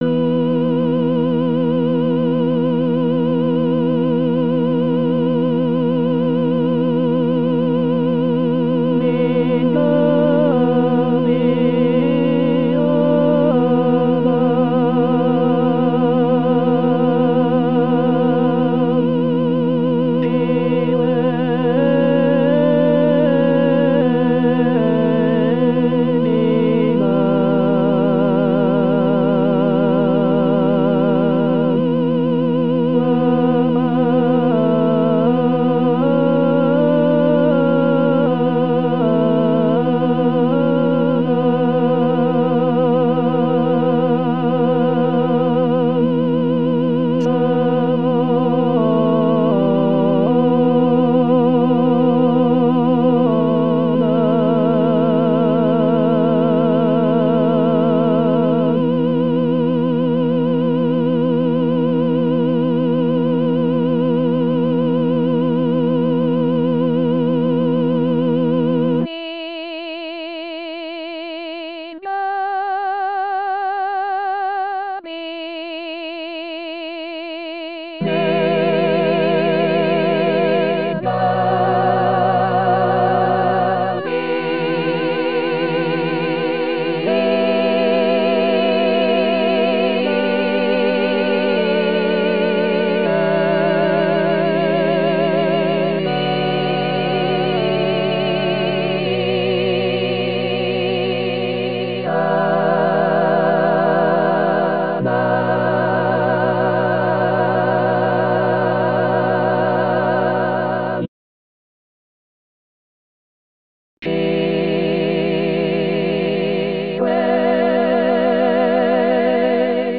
Full choir